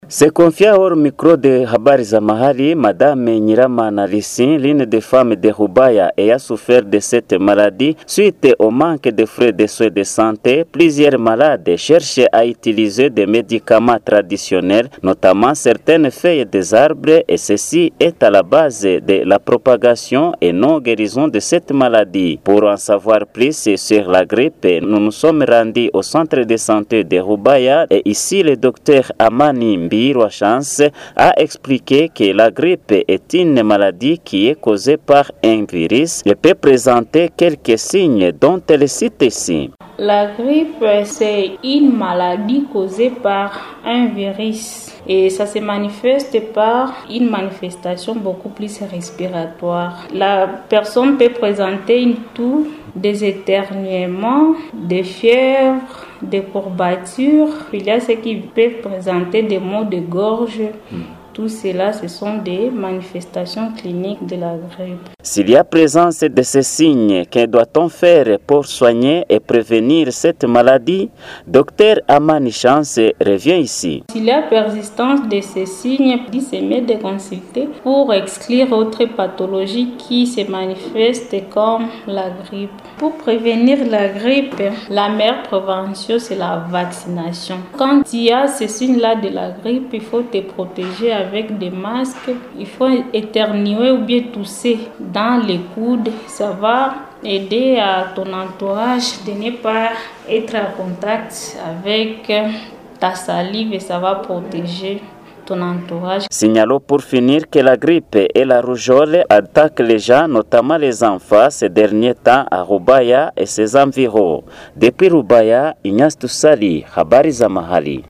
depuis Rubaya